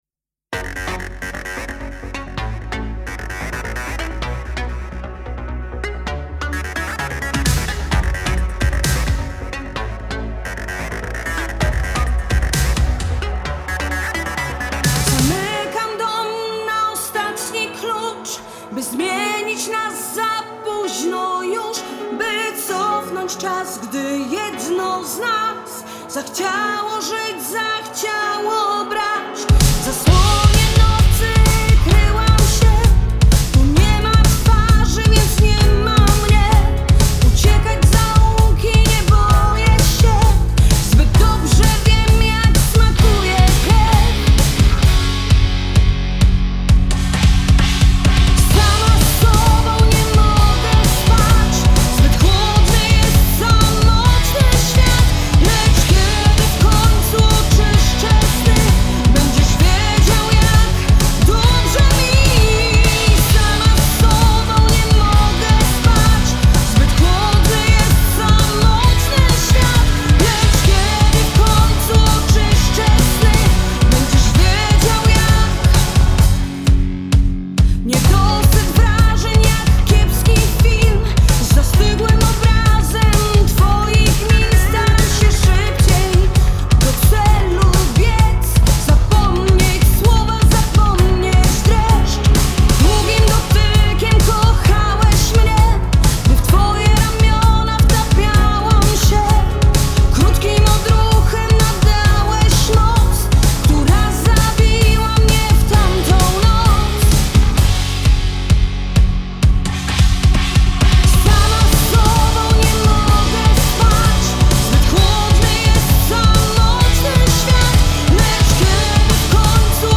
Gatunek: Blues.